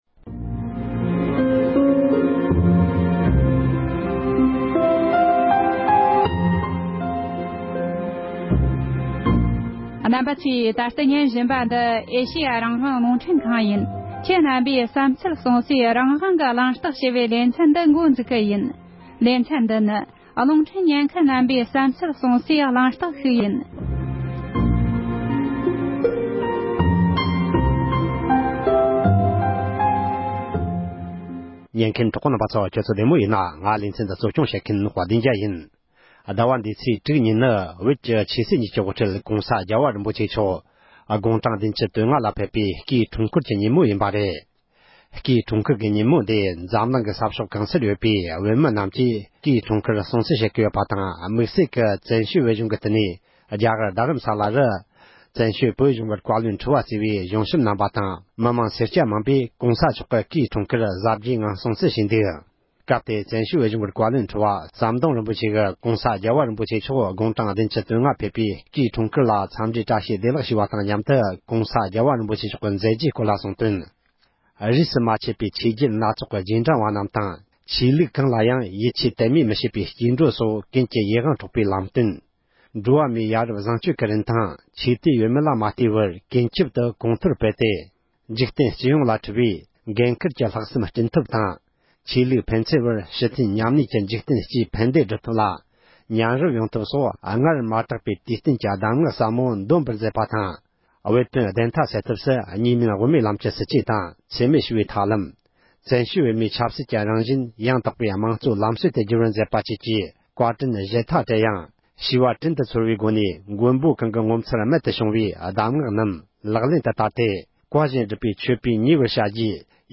༄༅༎དེ་རིང་གི་རང་དབང་གི་གླེང་སྟེགས་ཞེས་པའི་ལེ་ཚན་ནང་དུ། བོད་མི་རྣམས་ཀྱིས་༸གོང་ས་༸སྐྱབས་མགོན་ཆེན་པོ་མཆོག་དགུང་ལོ་༧༥་བཞེས་པའི་སྐུའི་འཁྲུངས་སྐར་དུས་ཆེན་སྲུང་བརྩི་ཞུ་བཞིན་པའི་སྐབས་དང་བསྟུན་ནས། ༸གོང་ས་མཆོག་གི་མཛད་རྗེས་ངོ་སྤྲོད་དང་དེ་བཞིན་ཨ་མདོ་མགོ་ལོག་ས་ཁུལ་གྱི་གཞས་མ་ཞིག་གིས། ༸གོང་ས་མཆོག་གི་སྐུའི་འཁྲུངས་སྐར་ལ་གླུ་གཞས་ཤིག་ཕུལ་ཡོད་པའི་སྐོར།